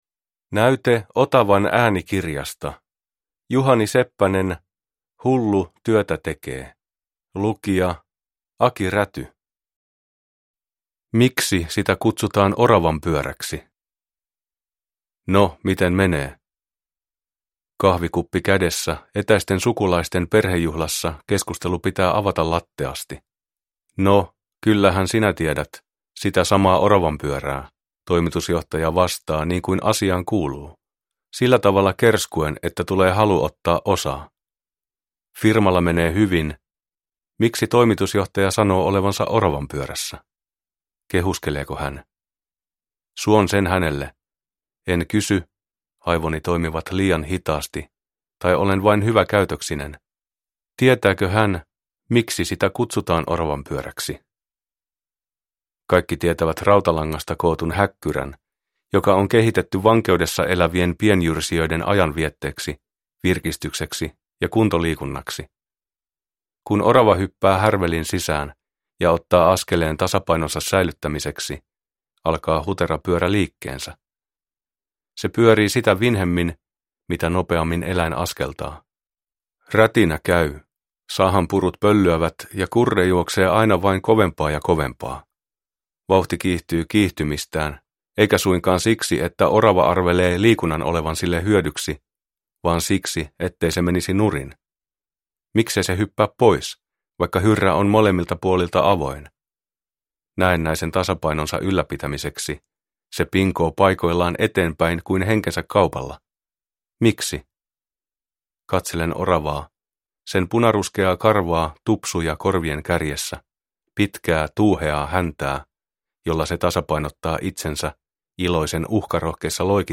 Hullu työtä tekee – Ljudbok – Laddas ner